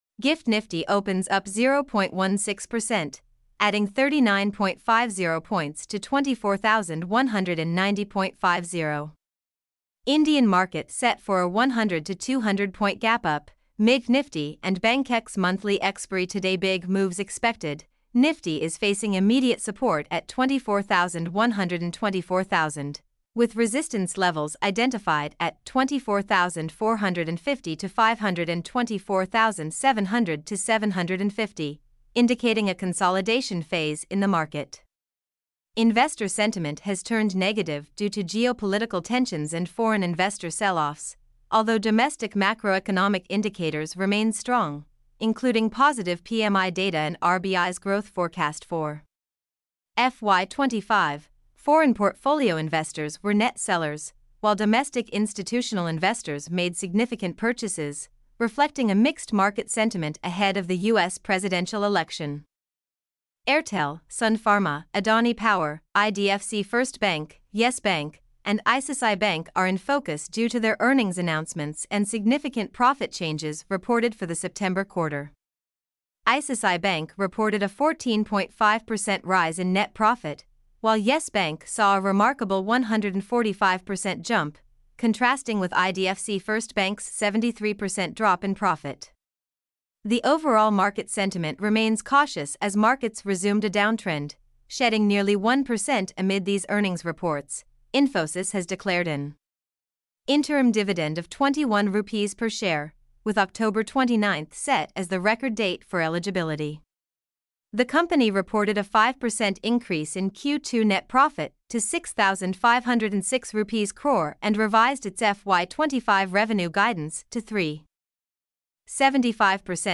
mp3-output-ttsfreedotcom-17.mp3